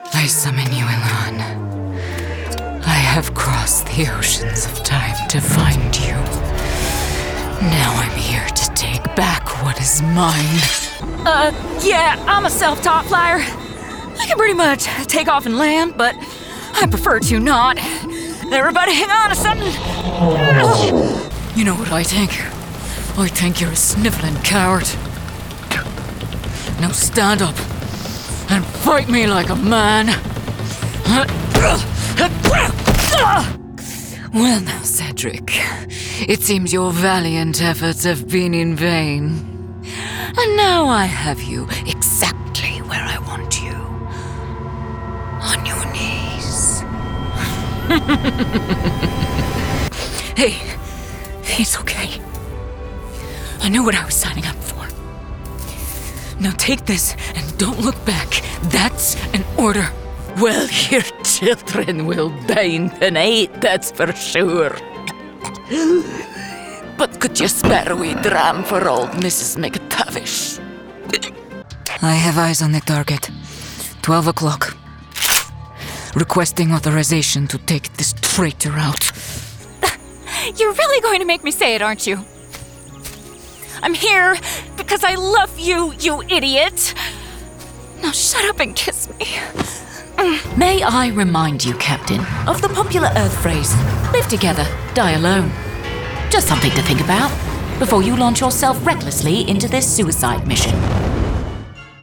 Teenager, Young Adult, Adult, Mature Adult
Has Own Studio
GAMING 🎮